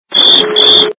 При прослушивании Пения - Сверчка качество понижено и присутствуют гудки.
Звук Пения - Сверчка